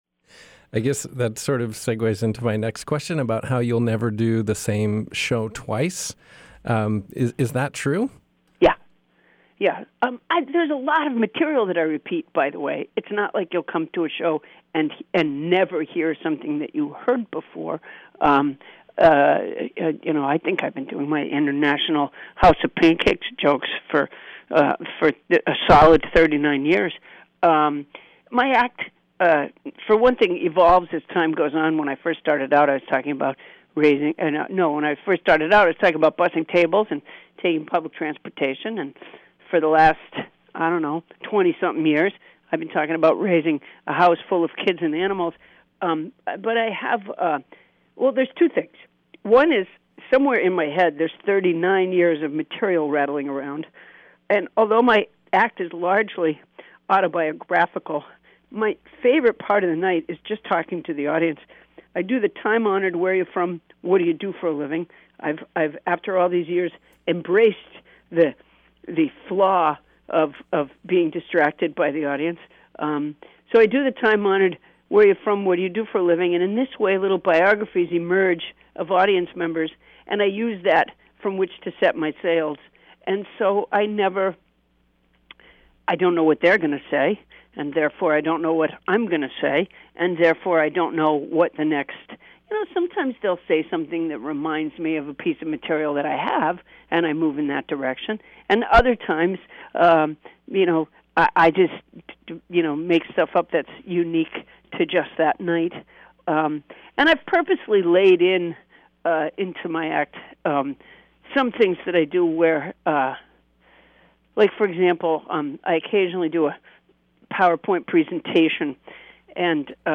Interview: Paula Poundstone talks beaver dens, improv and using her audience
I gave her a call and we talked about beaver dens, “Wait Wait … Don’t Tell Me!” and more.